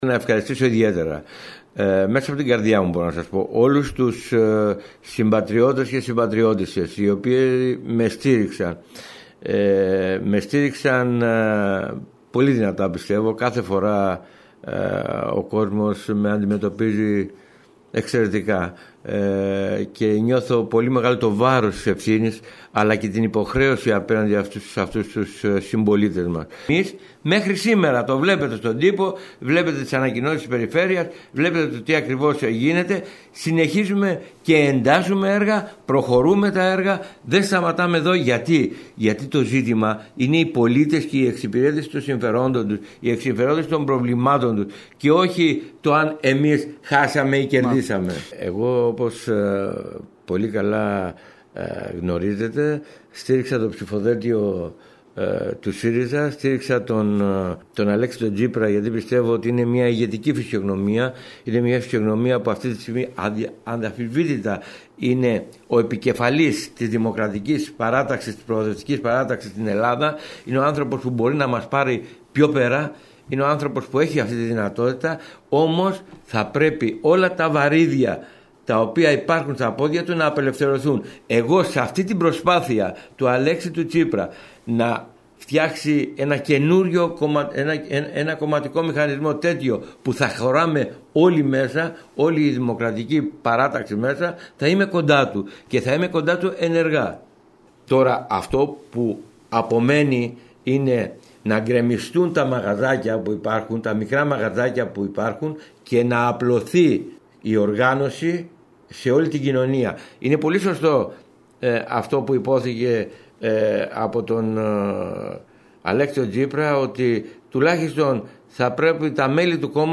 Την πρώτη του συνέντευξη μετά τις εκλογές, έδωσε σήμερα στην ΕΡΤ Κέρκυρας, ο Αντιπεριφερειάρχης Αλέκος Μιχαλάς.